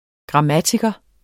Udtale [ gʁɑˈmatigʌ ]